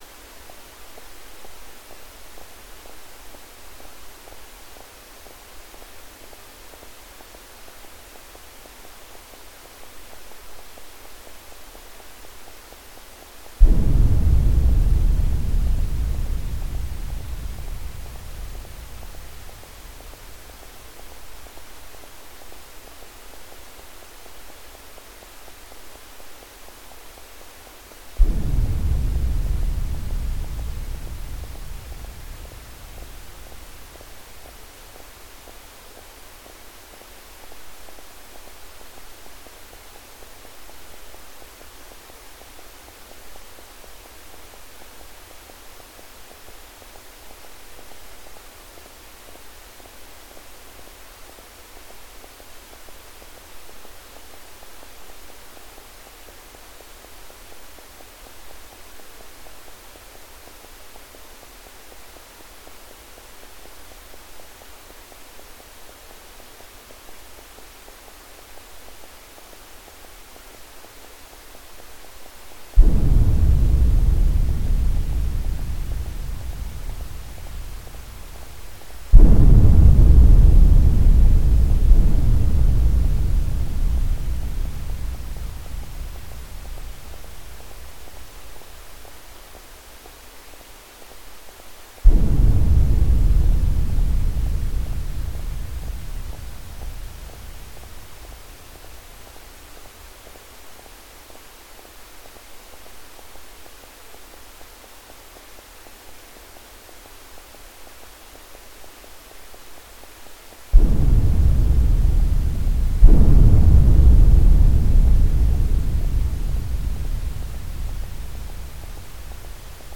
Textural Étude